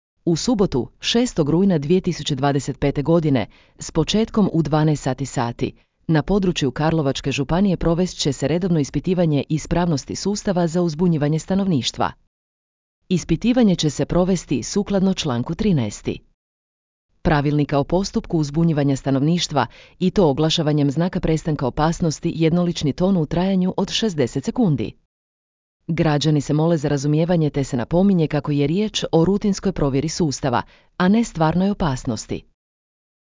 Testiranje sustava za uzbunjivanje – oglasit će se znak prestanka opasnosti
Ispitivanje će se provesti sukladno članku 13. Pravilnika o postupku uzbunjivanja stanovništva i to oglašavanjem znaka prestanka opasnosti – jednolični ton u trajanju od 60 sekundi.